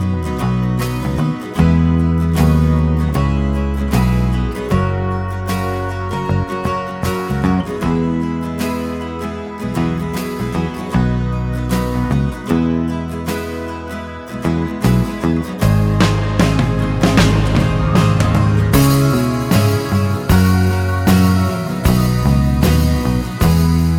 No Harmony Pop (1960s) 3:58 Buy £1.50